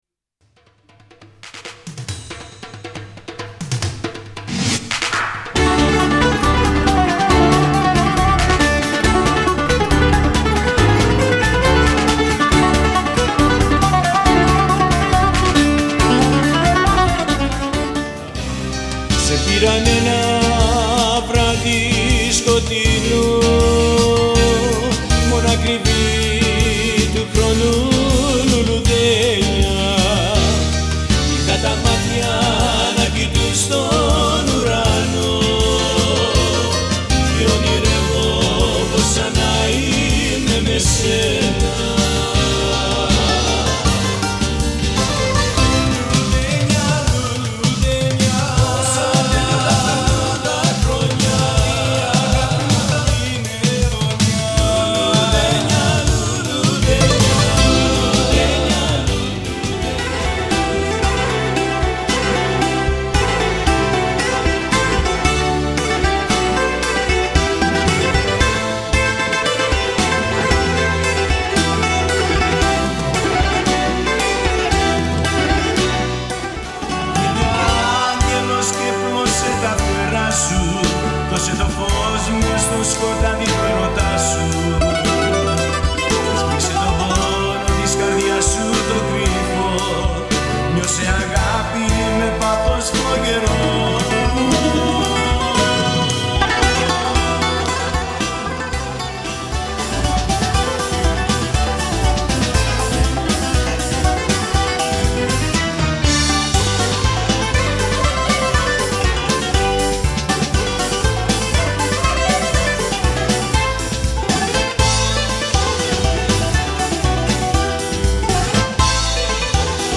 Vocals
Bouzouki
Keyboards, Bass, Clarinet, Percussion